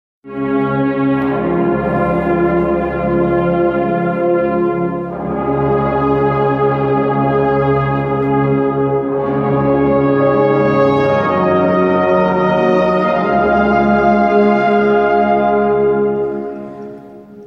Am Abend des Christi Himmelfahrt-Tages werden die Schreine um 19 Uhr feierlich aus der Krypta in die Mitte der Kirche erhoben. Dazu wird durch die örtliche Bläsergruppe ein
dreifacher Tusch, der ursprünglich aus dem Oratorium "Paulus" (1836) von Felix Mendelssohn-Bartholdy (1809-1857) stammt, im Wechsel mit dem Saturninenlied, erklingen.
saturn_tusch.mp3